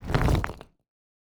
Stone Move 5_4.wav